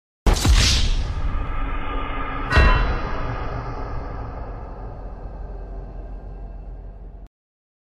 GTA V Wasted_Busted - Gaming Sound Effect (HD)
Category: Sound FX   Right: Personal